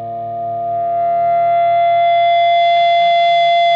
PRS FBACK 9.wav